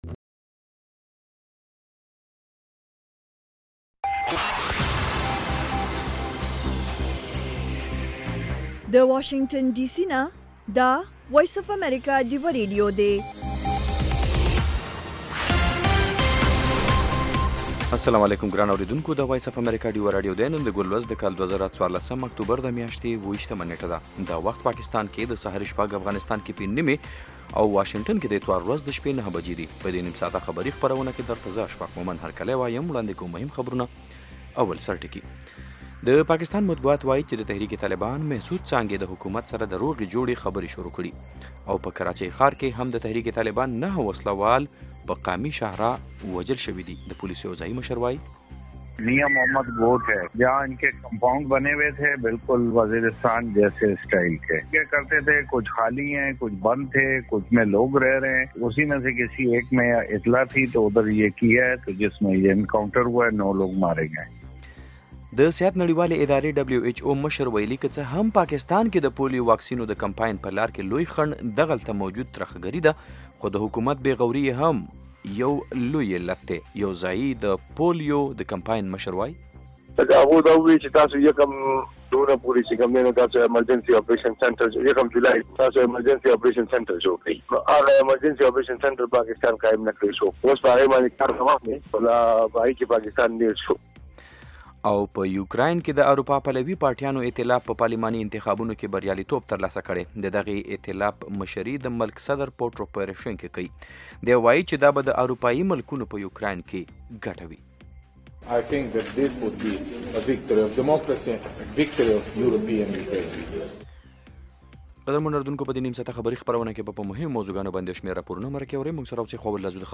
خبرونه - 0100
د وی او اې ډيوه راډيو سهرنې خبرونه چالان کړئ اؤ د ورځې د مهمو تازه خبرونو سرليکونه واورئ.